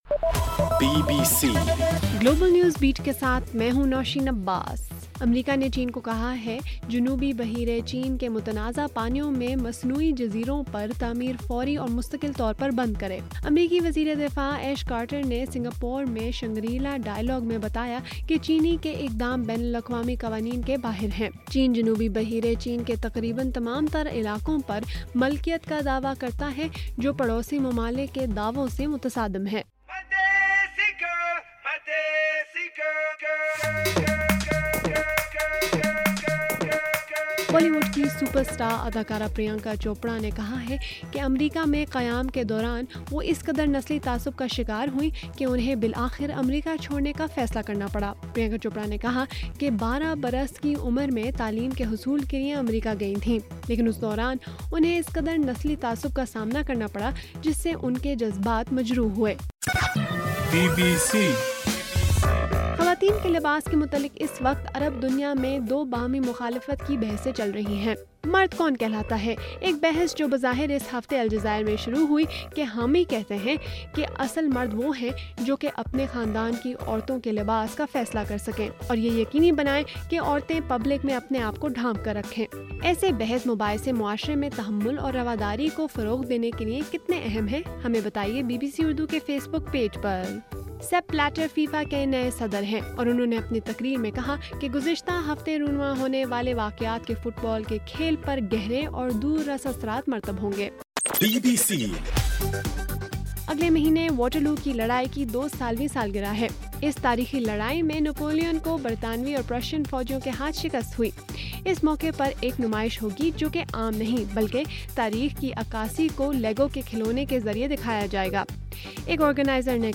مئی 30: رات 12 بجے کا گلوبل نیوز بیٹ بُلیٹن